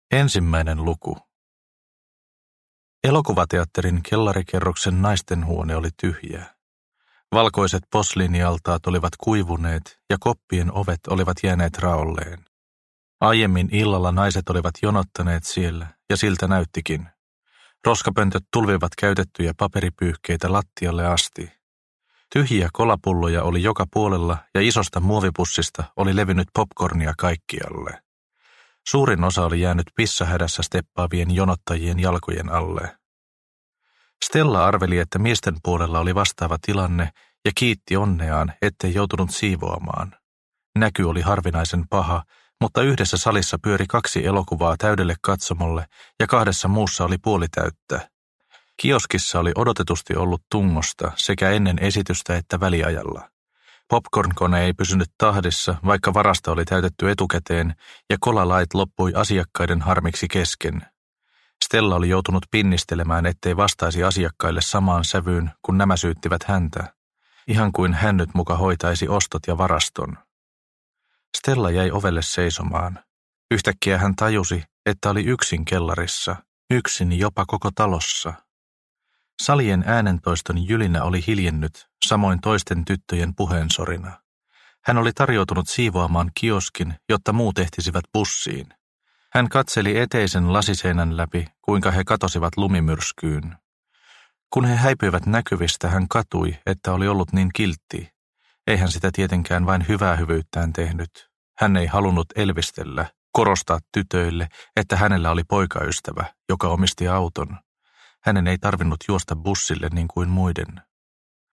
Synninpäästö – Ljudbok – Laddas ner